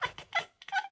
minecraft / sounds / mob / cat / beg3.ogg
beg3.ogg